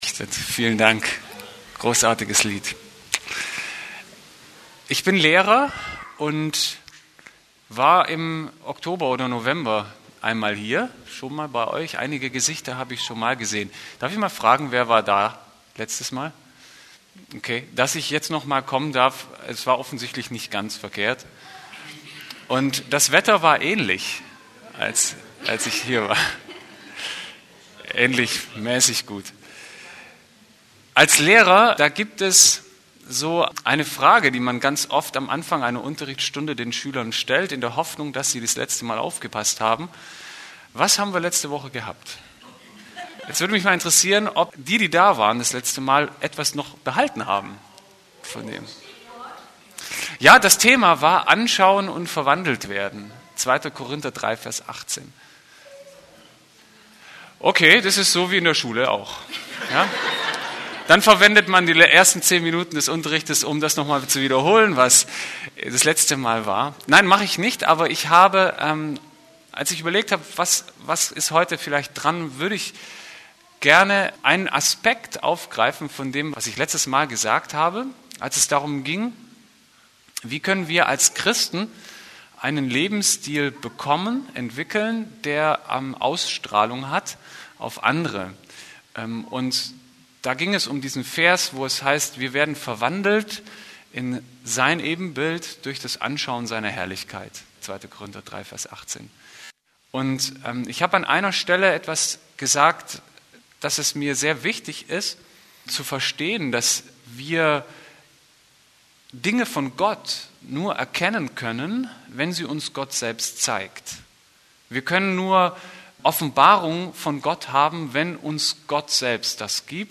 Geistliches Sehvermögen ist nicht selbstverständlich – aber ein erklärter Wunsch Gottes für seine Leute. Wie ich zu einer geistlichen Sichtweise gelangen kann, ist Thema meiner Predigt über die Geschichte der Emmaus-Jünger aus Lukas 24.